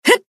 贡献 ） 分类:蔚蓝档案语音 协议:Copyright 您不可以覆盖此文件。
BA_V_Wakamo_Battle_Shout_2.ogg